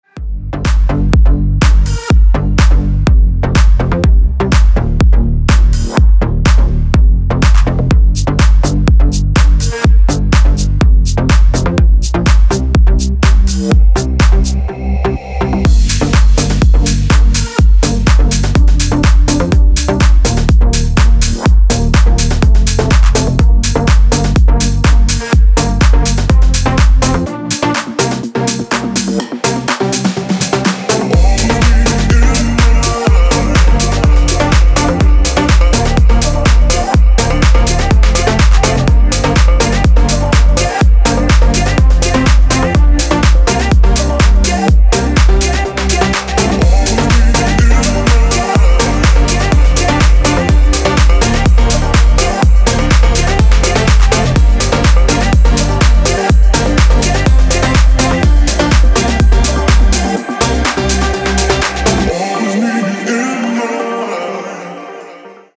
• Качество: 224, Stereo
мужской голос
громкие
dance
Electronic
EDM
электронная музыка
house